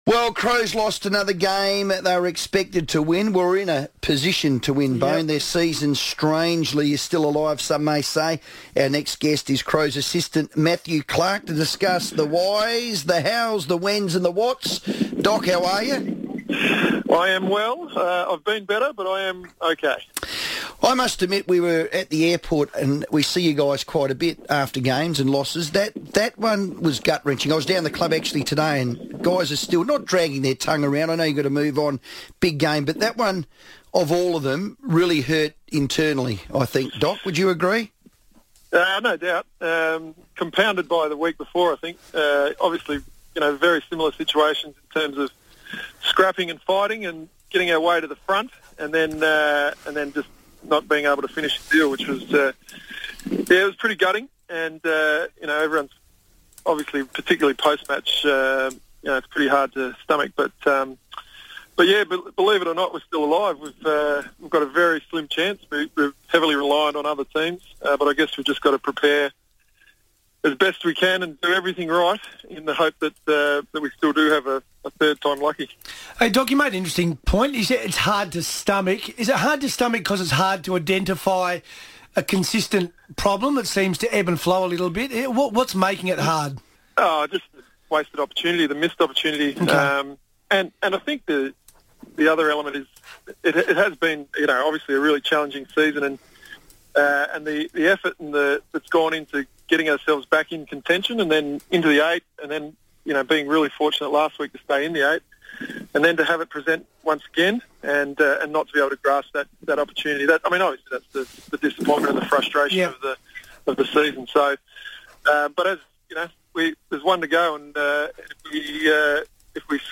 joined the FIVEaa Sports Show ahead of Adelaide's final minor round clash against the Saints on Sunday.